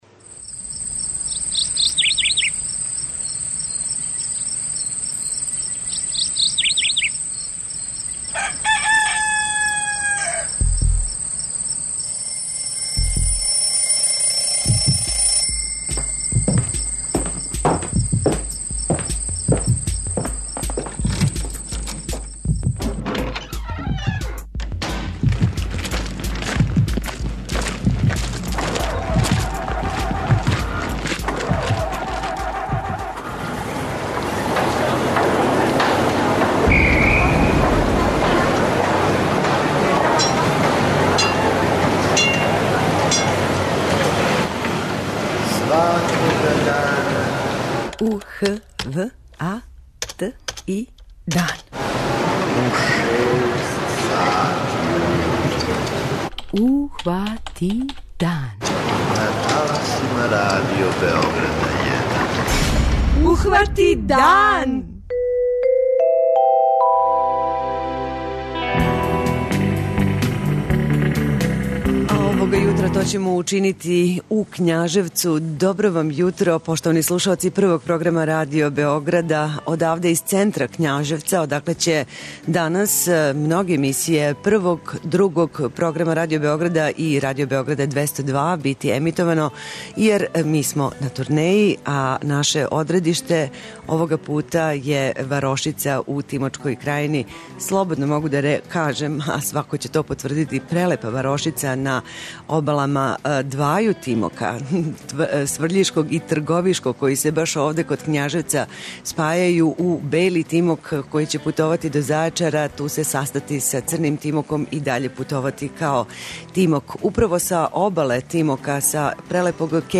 Екипа Радио Београда је у Књажевцу одакле и реализујемо јутарњи програм.